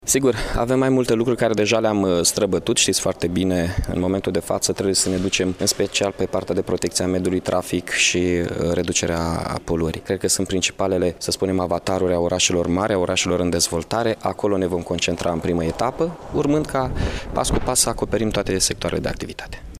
Evenimentul a avut loc cu prilejul desfăşurării Caravanei Smart City în localitate.
Primarul Mihai Chirica a adăugat că administraţia publică are nevoie de digitalizare în ritm accelerat: